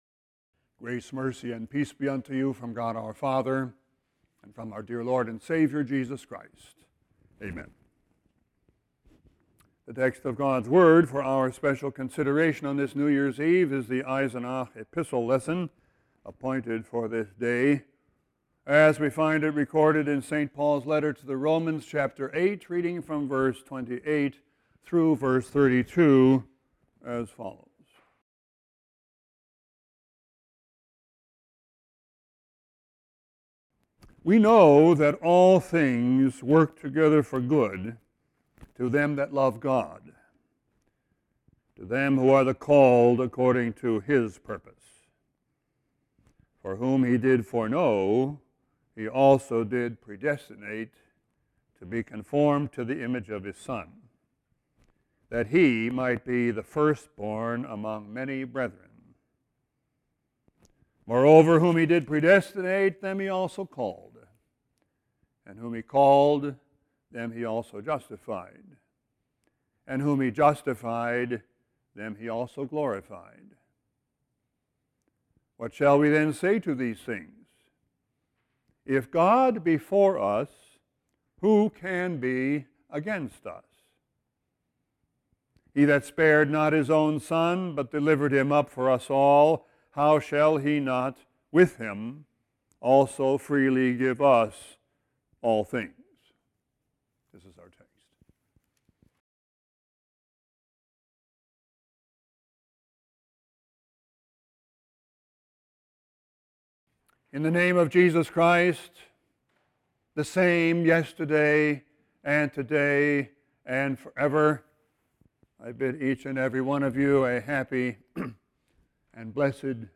Text: Romans 8:28-32 This text will be replaced by the JW Player Right click & select 'Save link as...' to download entire Sermon audio Right click & select 'Save link as...' to download entire Sermon video